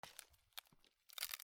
古びた木片 小さな物音
『シュル ミシ』